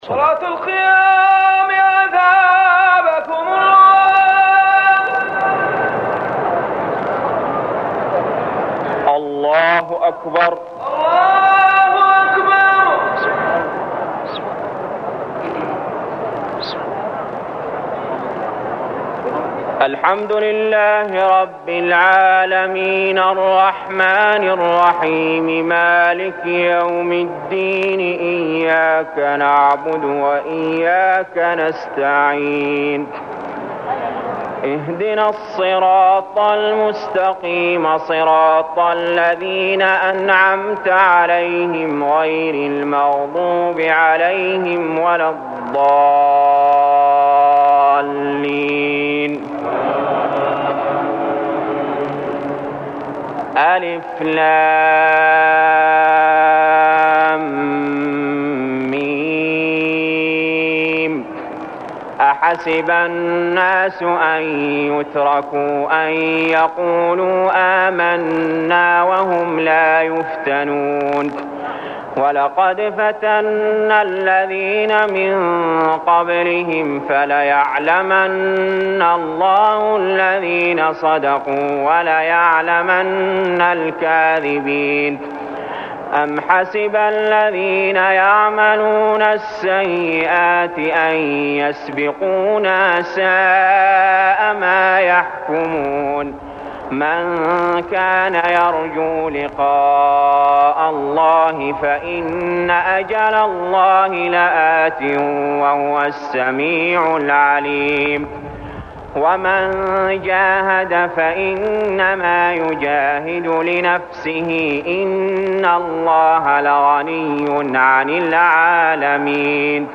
المكان: المسجد الحرام الشيخ: علي جابر رحمه الله علي جابر رحمه الله العنكبوت